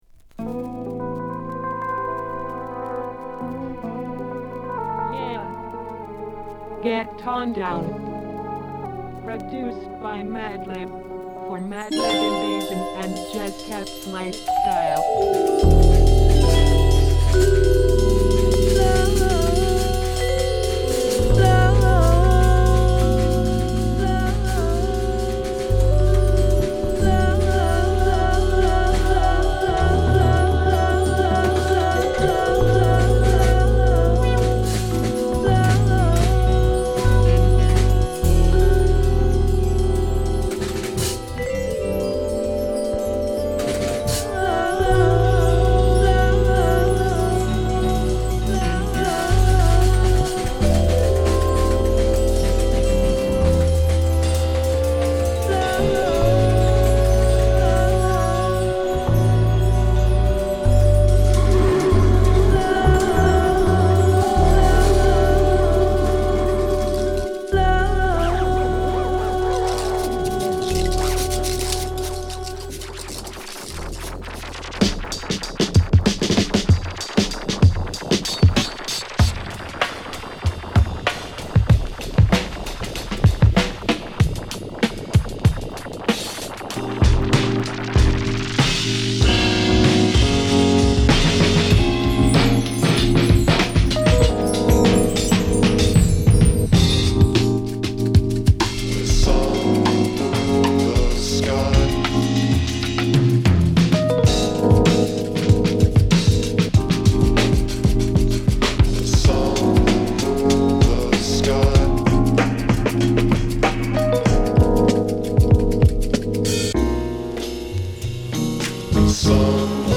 ＊試聴はB→A2です。